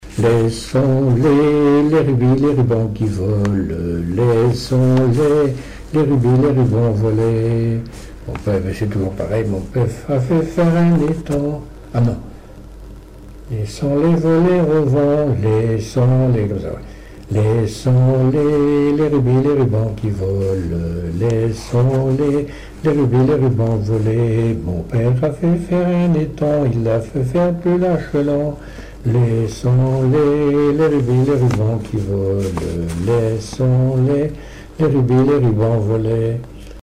gestuel : à marcher
circonstance : conscription
Genre laisse
Catégorie Pièce musicale inédite